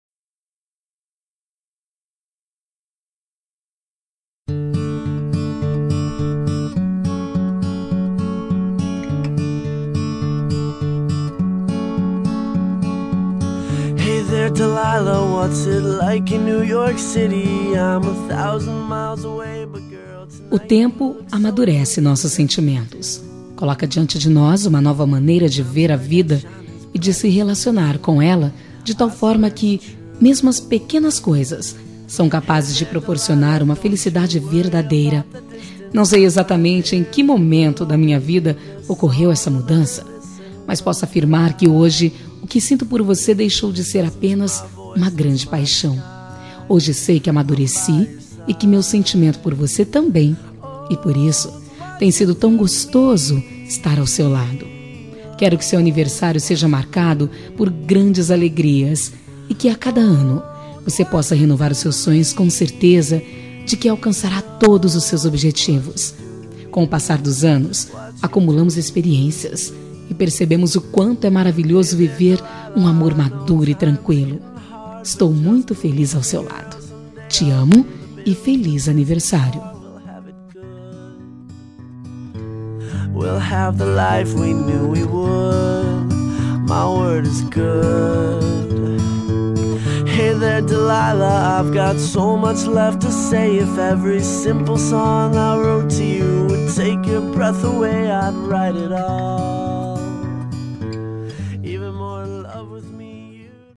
Voz Feminina
Código: 0020 – Música: Hey There Delilah – Artista: Plain White Ts